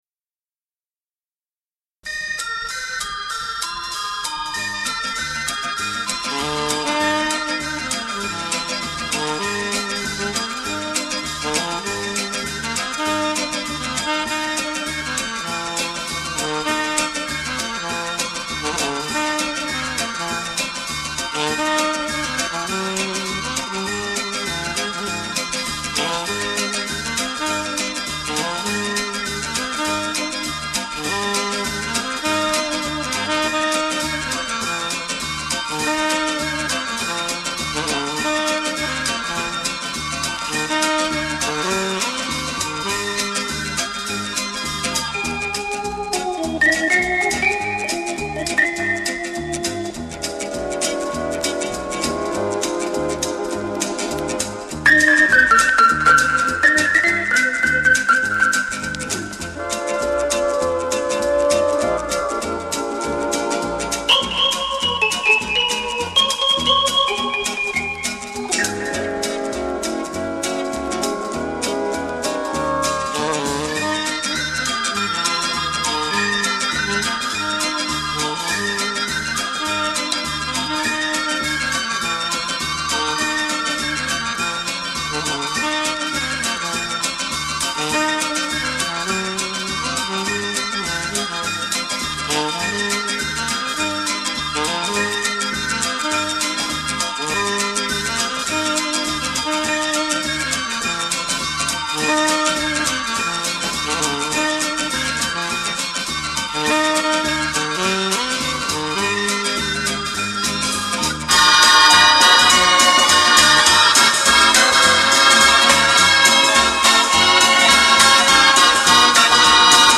FOXTROT